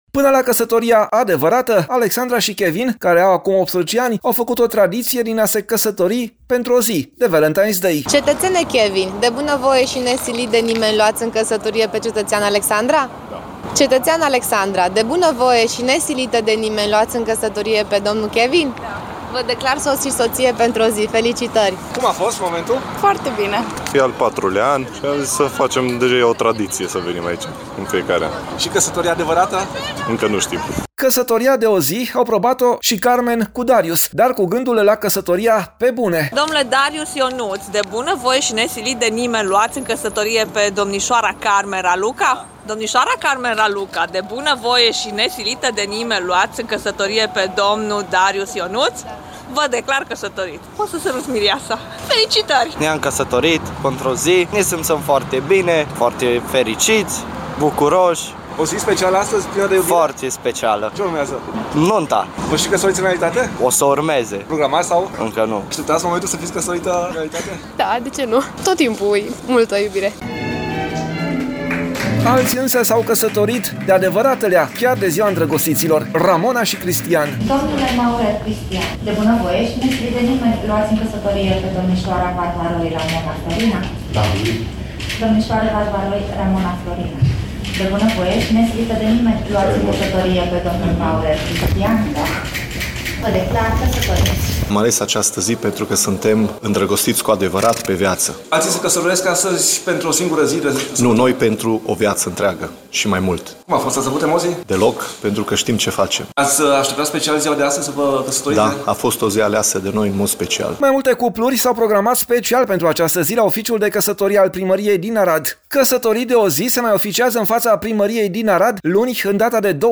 Pentru a marca ziua de 14 februarie sau Valentine’s Day, în fața primăriei din Arad au fost amenajate decoruri romantice, unde cei doritori au avut ocazia să își unească destinele de probă.